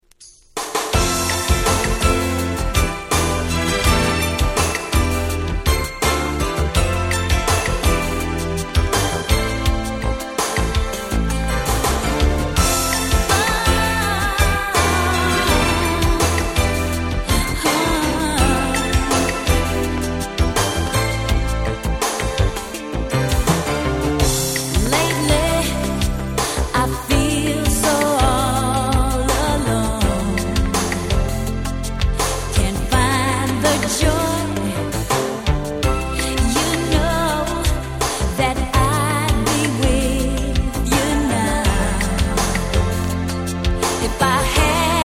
89' Super Slow Jam !!
80's Slow Jam スロウジャム バラード